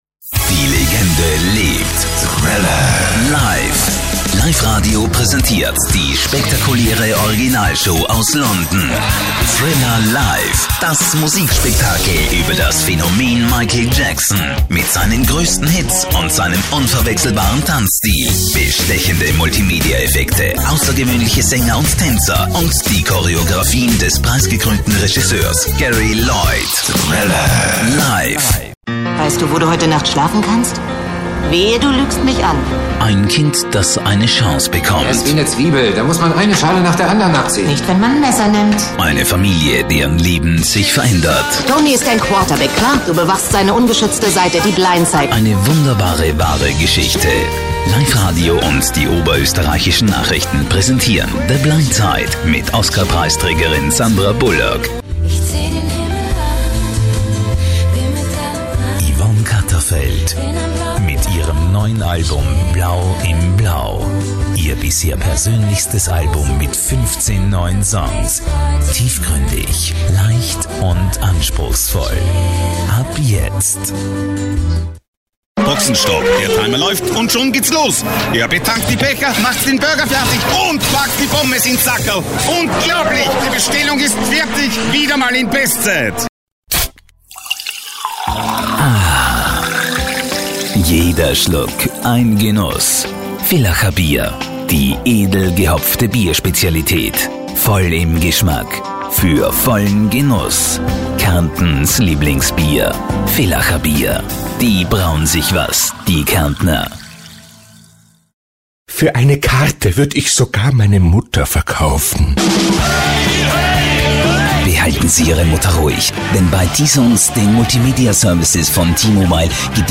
Male
Freundliche tiefe Männerstimme, akzentfrei und hochdeutsch.
Radio Commercials
Showreel Mit Mehreren Spots